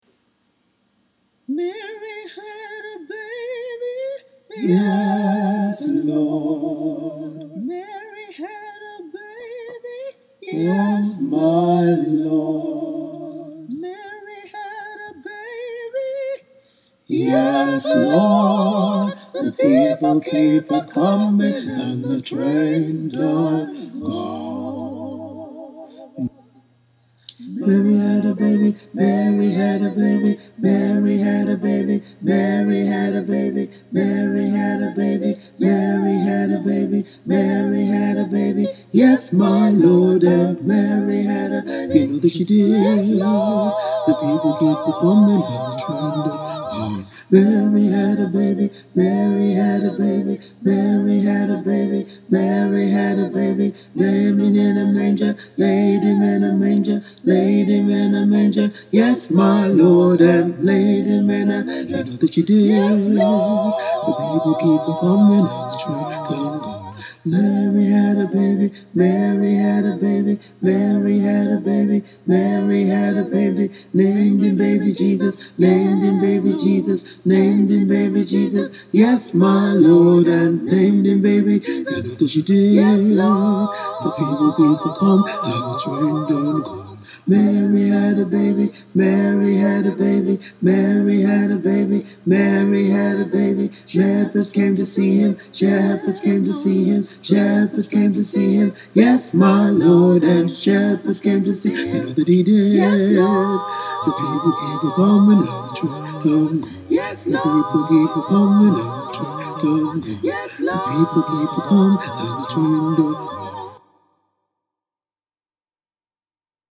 mary-had-a-baby-bass.mp3